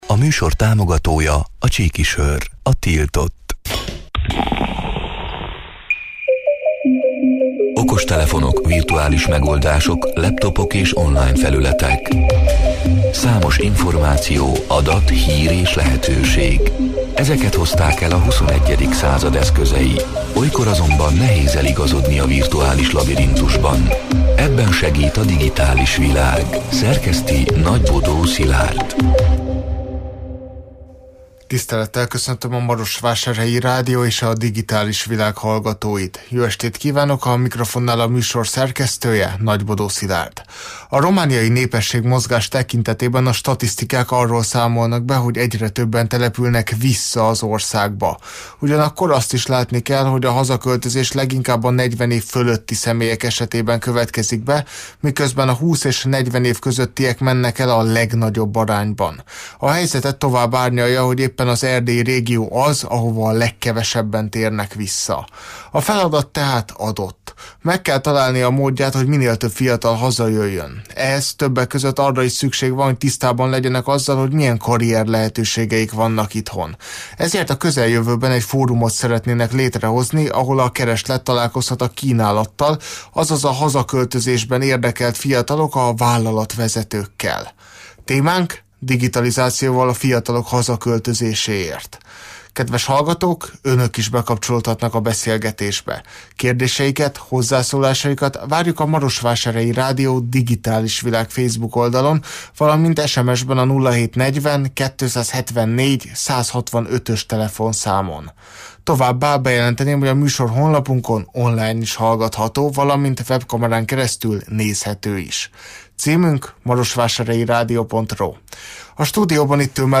A Marosvásárhelyi Rádió Digitális Világ (elhangzott: 2024. október 8-án, kedden este nyolc órától élőben) c. műsorának hanganyaga: A romániai népességmozgás tekintetében a statisztikák arról számolnak be, hogy egyre többen települnek vissza az országba.